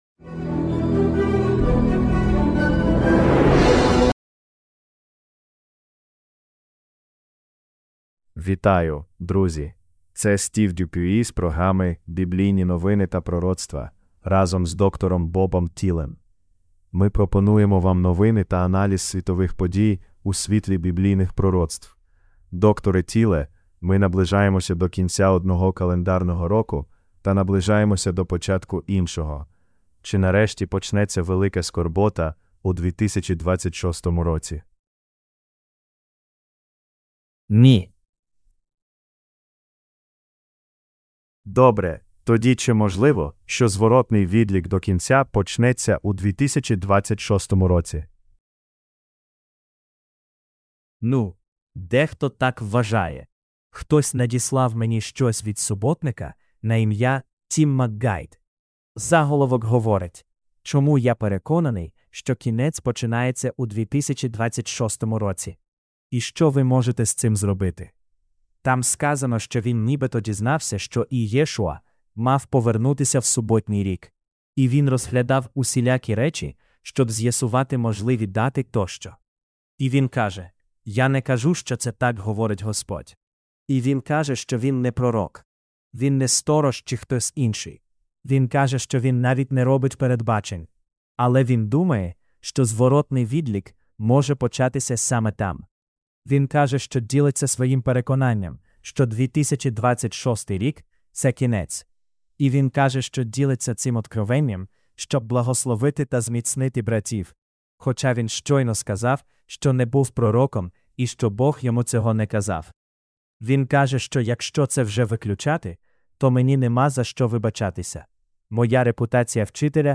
Ukrainian Sermonette – Bible Prophecy News